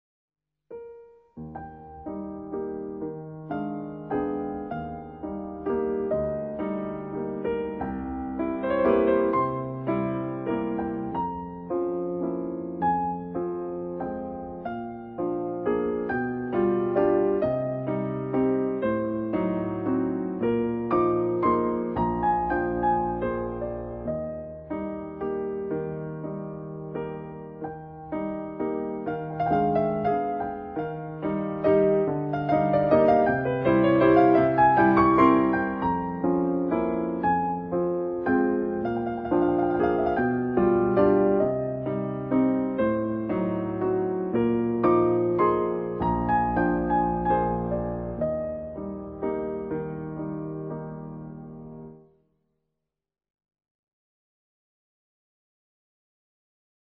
classical piano album